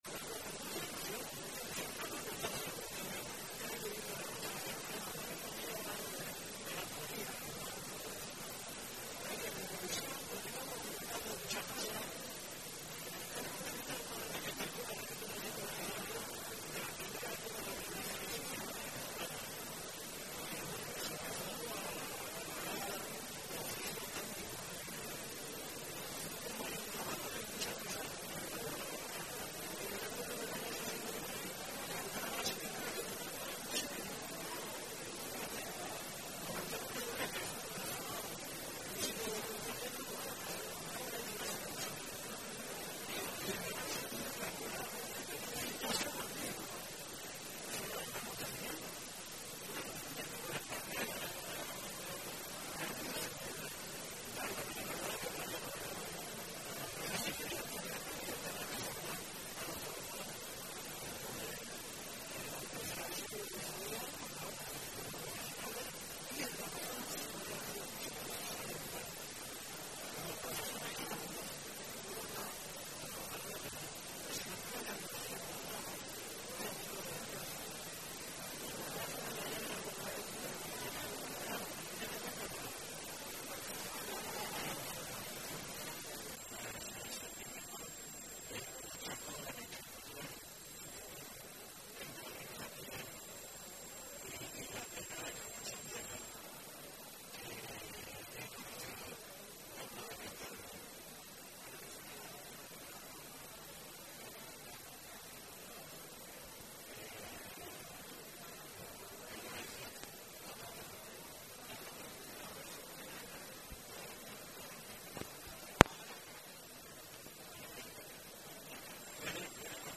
La alcaldesa de Cartagena, Noelia Arroyo, ha presidido el acto de apertura del programa de actividades con motivo del centenario de la Escuela de Aprendices de Bazán, que marca un siglo de excelencia en la formación profesional y el desarrollo industrial de la ciudad, y que se llevarán a cabo hasta el próximo 25 de octubre.
Declaraciones